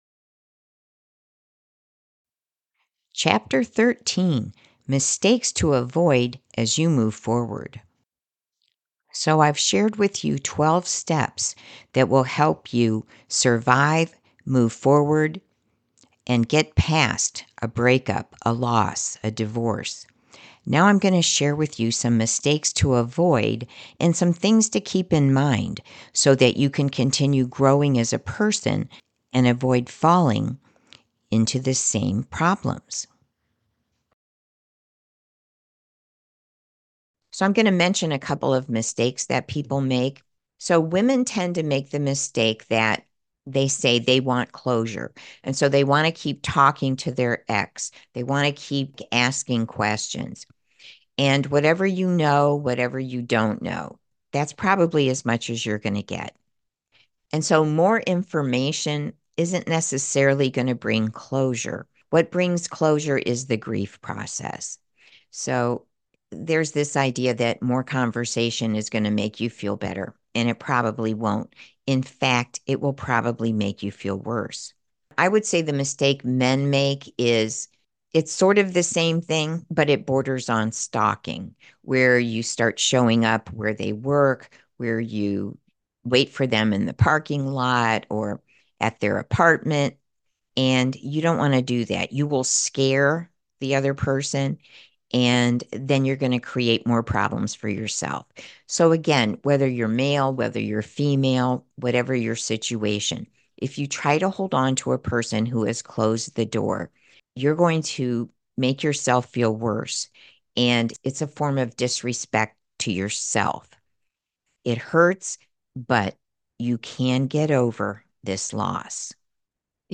AudioBook: 12 Steps to Survive a Break Up